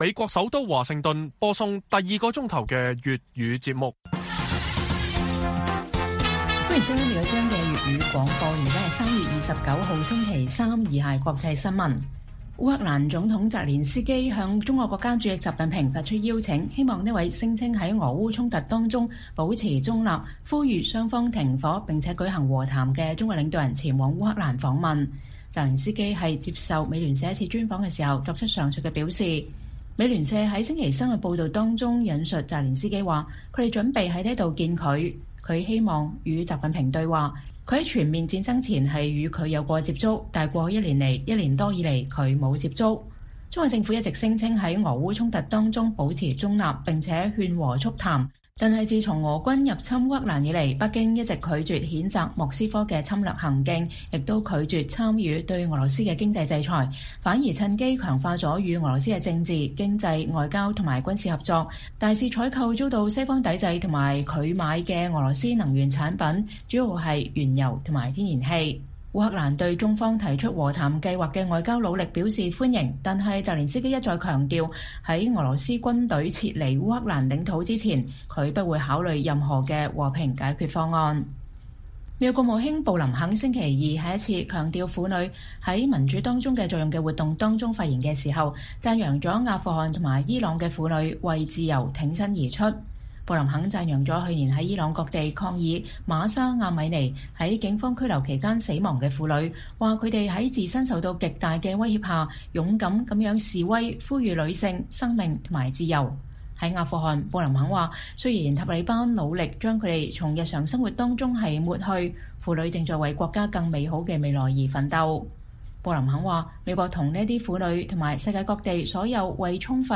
粵語新聞 晚上10-11點: 澤連斯基邀請習近平訪問烏克蘭